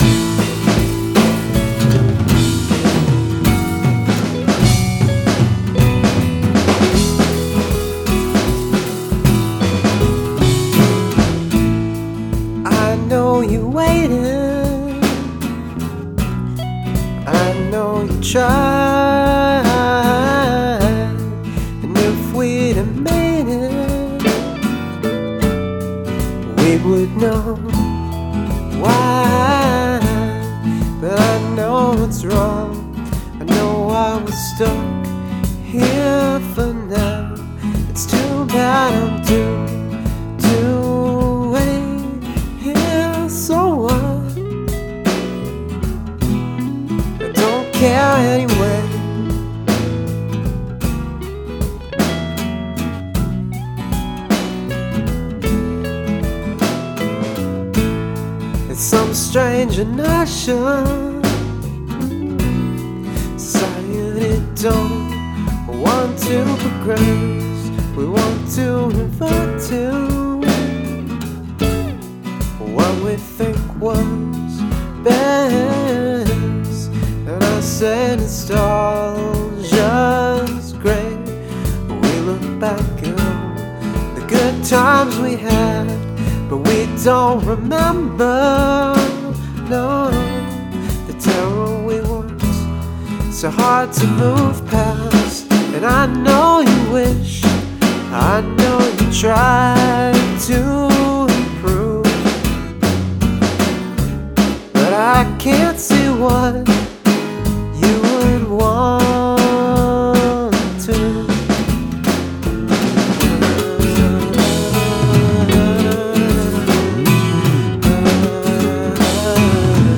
Chapel Hill NC Rock Band